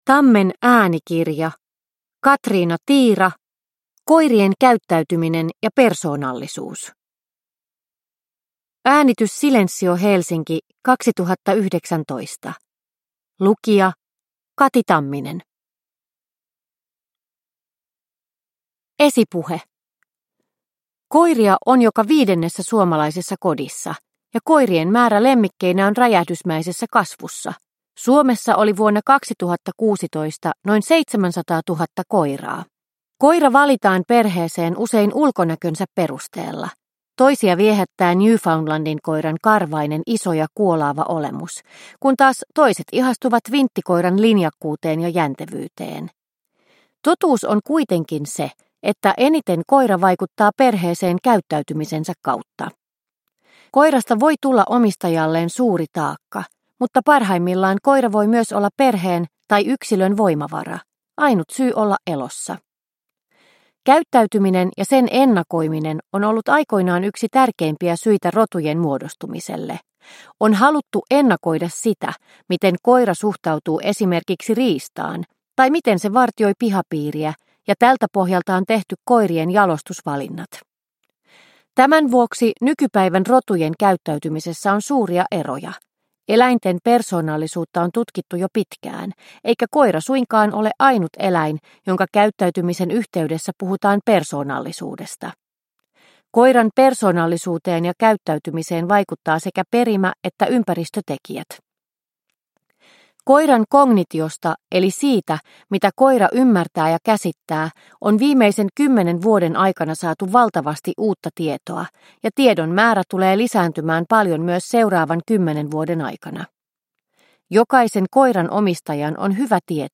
Koirien käyttäytyminen ja persoonallisuus – Ljudbok – Laddas ner